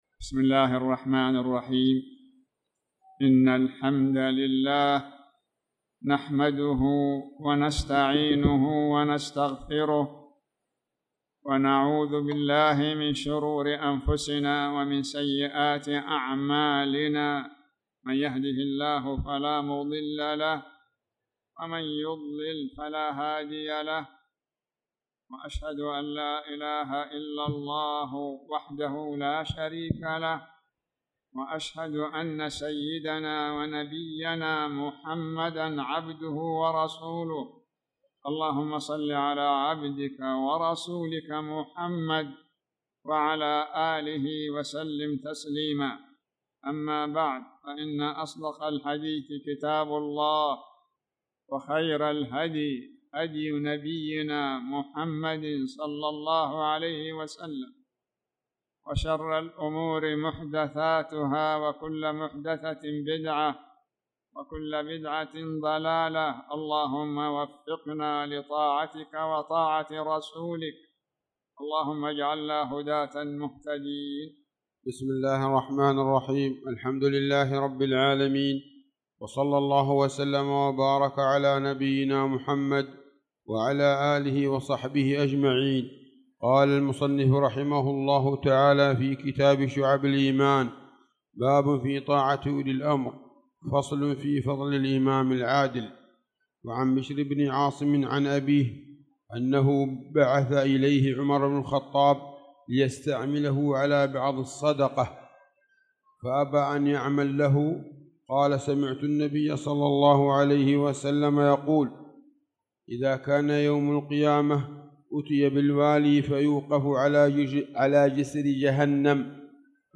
تاريخ النشر ٢١ شوال ١٤٣٧ هـ المكان: المسجد الحرام الشيخ